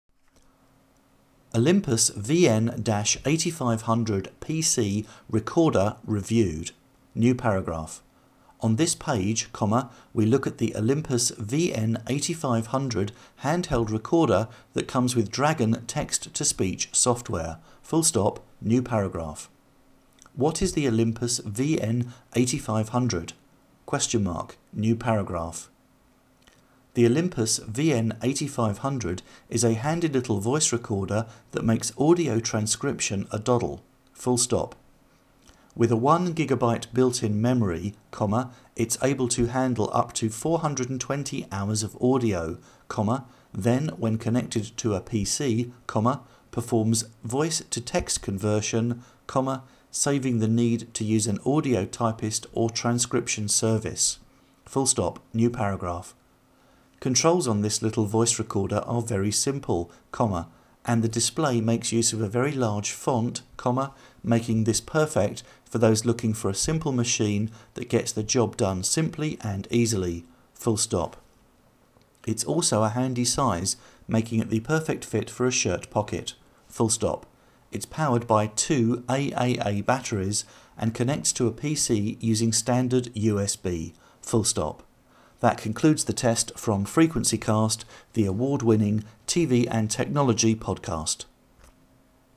The microphone is logically placed at the top of the recorder, and doesn't seem to pick up much in the way of hand noise.
We set the Olympus into DNS dictation mode, and read some test text.